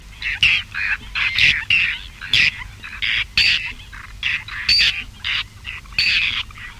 Guifette moustac
Chlidonias hybrida